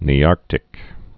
(nē-ärktĭk, -ärtĭk)